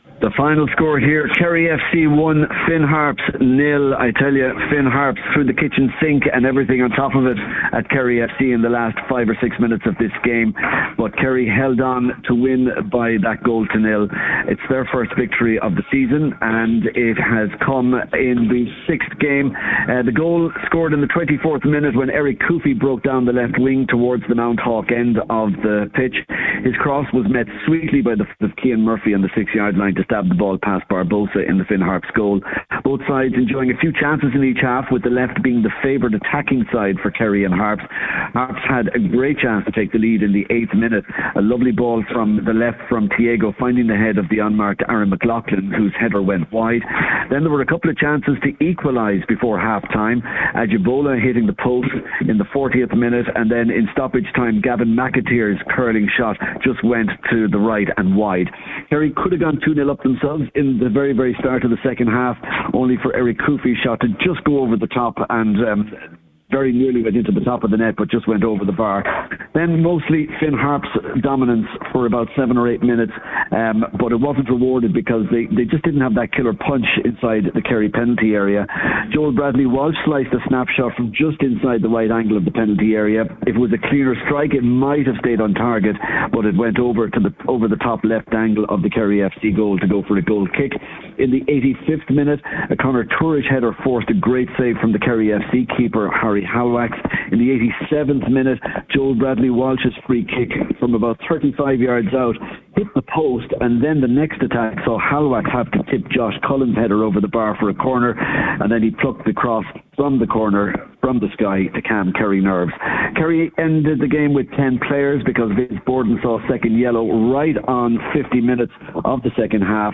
live at full time in Tralee for Highland Radio Sport…